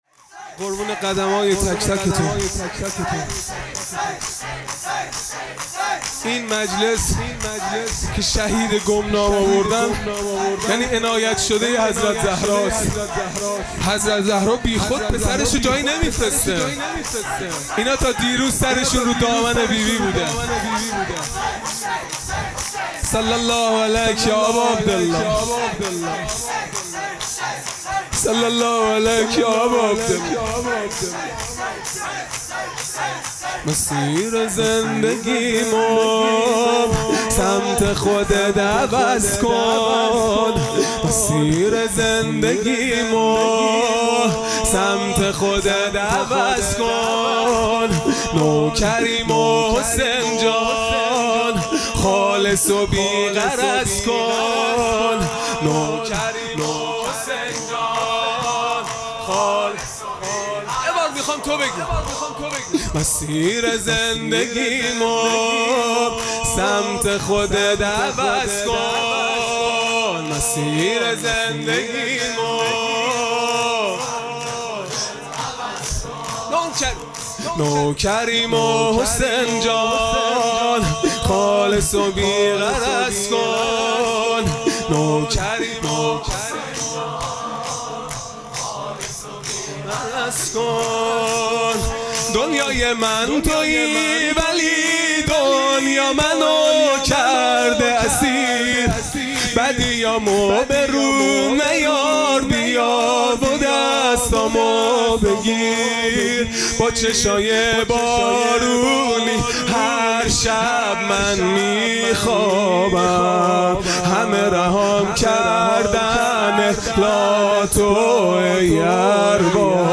خیمه گاه - هیئت بچه های فاطمه (س) - شور | مسیر زندگیمو سمت خودت عوض کن
فاطمیه دوم (شب اول)